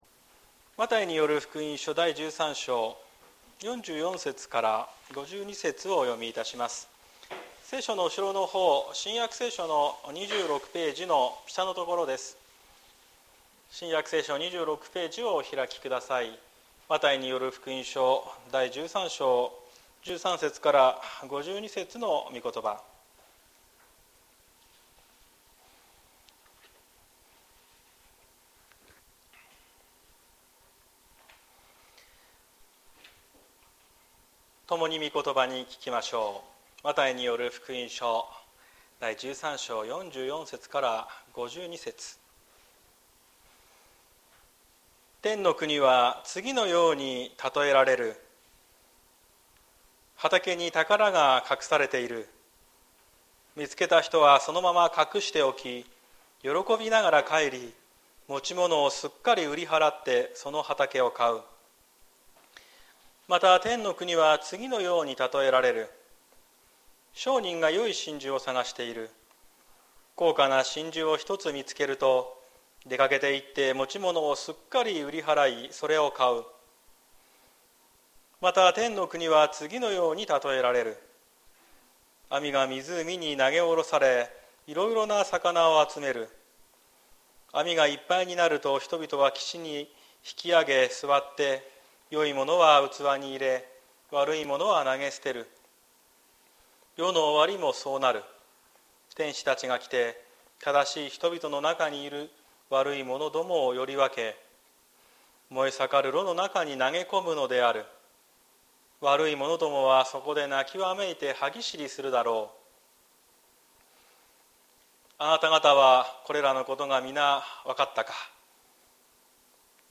2024年06月09日朝の礼拝「天の国のたとえ」綱島教会
説教アーカイブ。